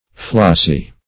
Flossy \Floss"y\ (?; 115), a.